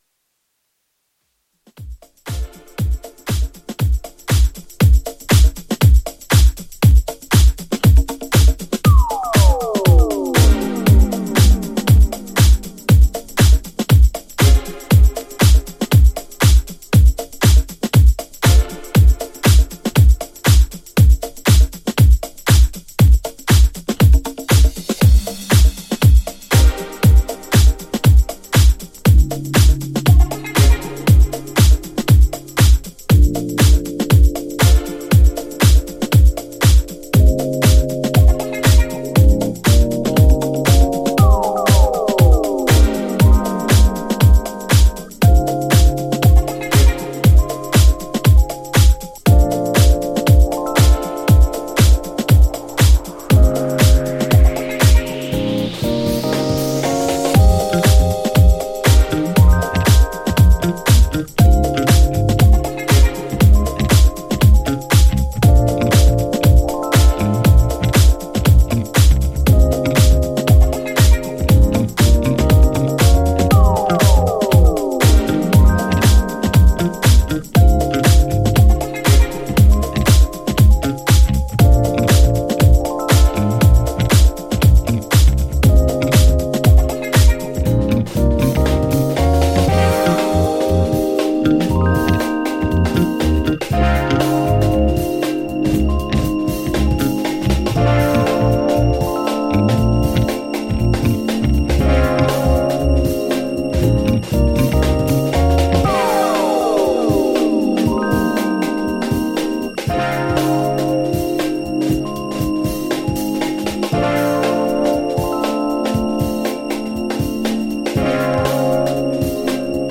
ジャンル(スタイル) NU DISCO / DISCO HOUSE / SOULFUL HOUSE